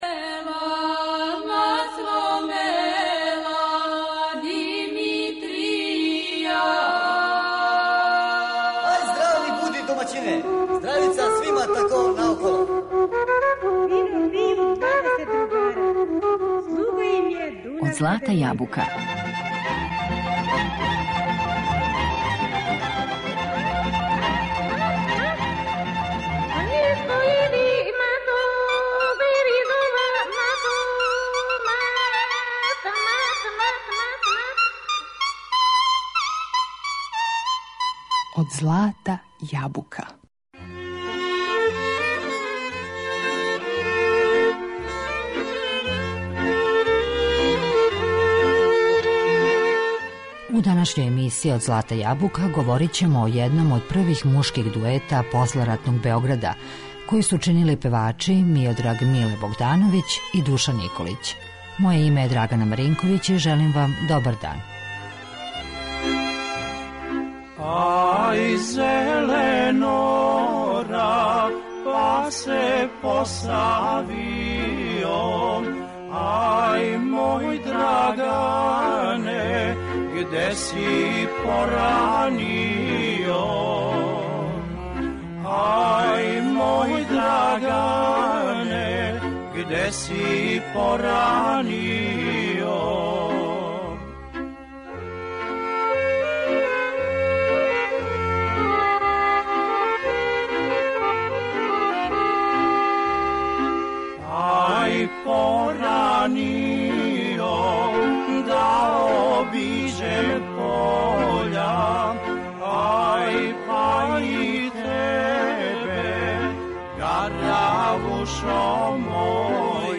Солистичку каријеру почели су још далеке 1944. године, а заједно су наступали двадесет година. Репертоар им је био састављен од претежно српских народних песама, погодних за двогласно певање, а сарађивали су са свим оркестрима који су тада били у Радио Београду.